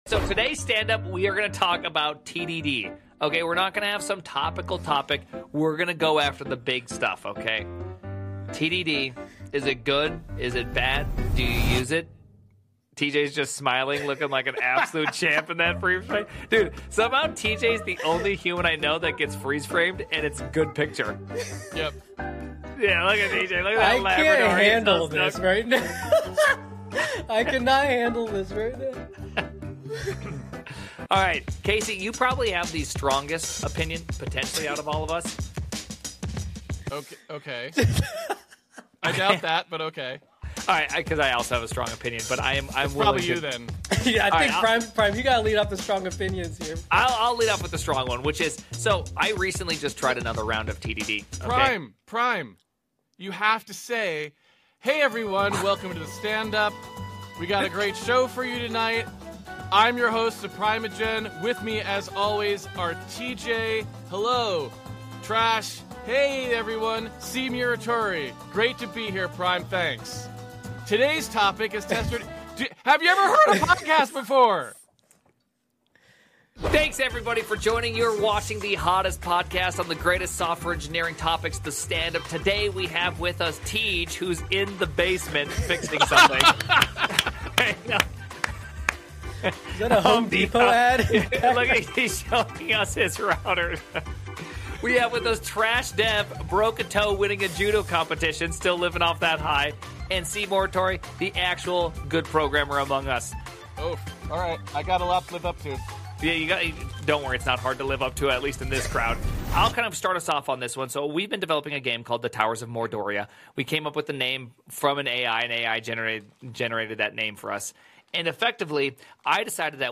The gang discusses Test Driven Development and laughs a lot.